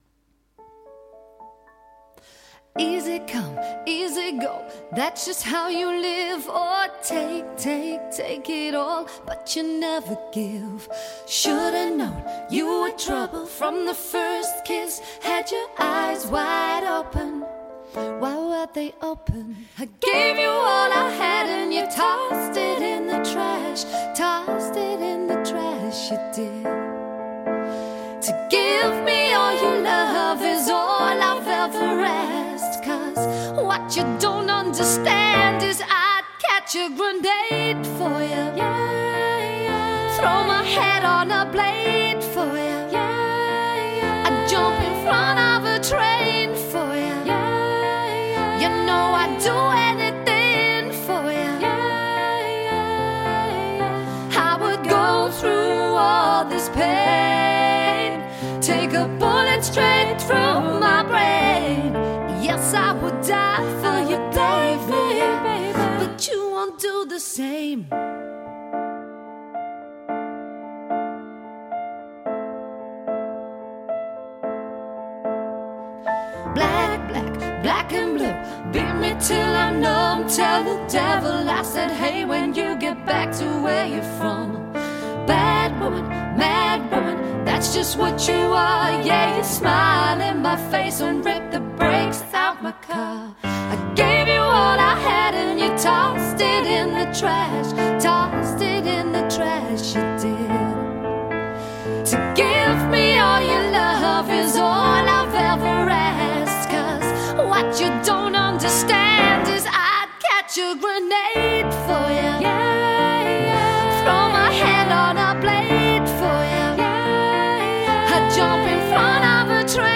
Featuring seamless female vocals and beautiful Piano...